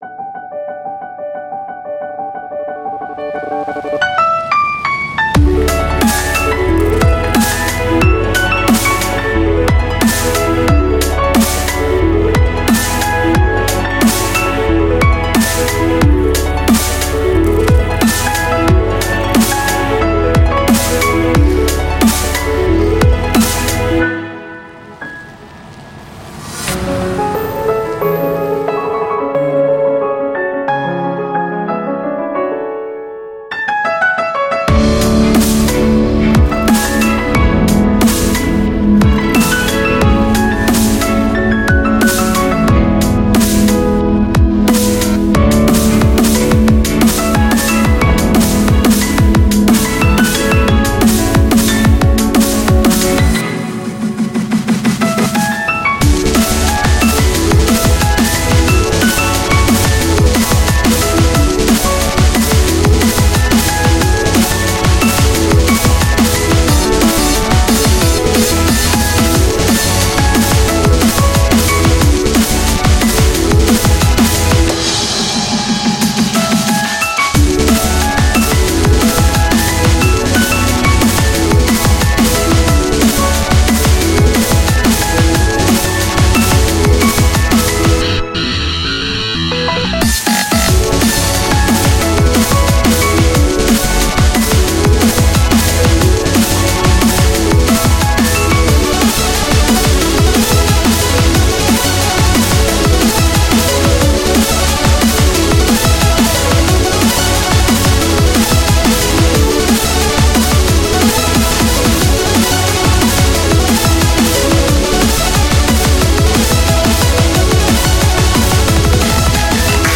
BPM90-180
Audio QualityPerfect (High Quality)
A pretty sorrowful artcore track.